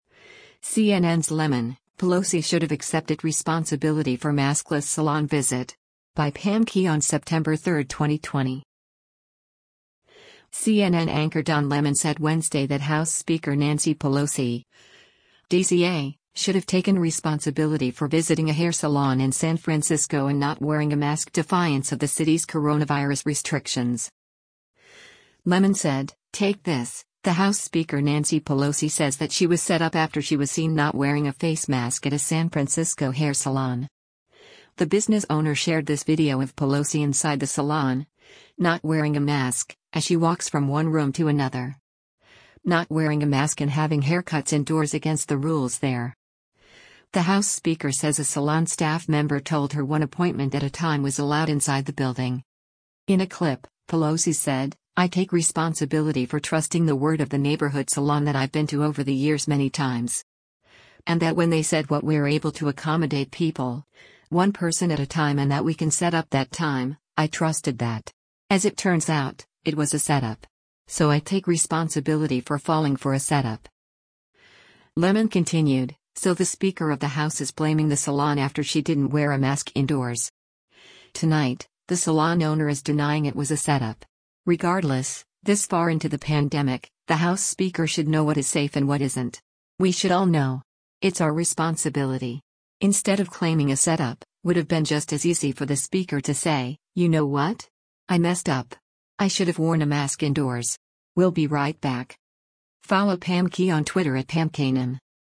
CNN anchor Don Lemon said Wednesday that House Speaker Nancy Pelosi (D-CA) should have taken responsibility for visiting a hair salon in San Francisco and not wearing a mask defiance of the cities coronavirus restrictions.